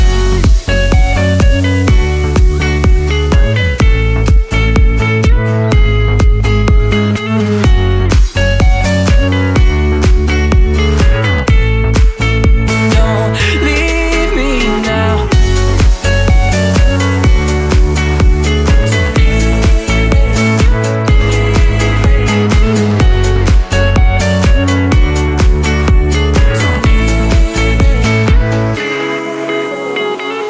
танцевальная